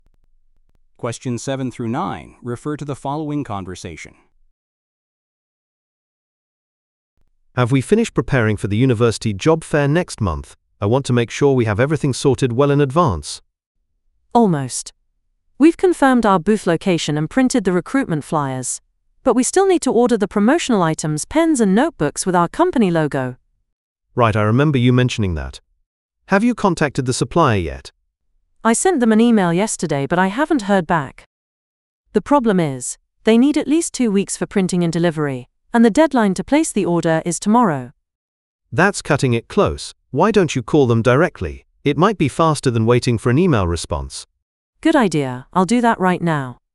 ※TOEICは、アメリカ/イギリス/オーストラリア/カナダ発音で出ます。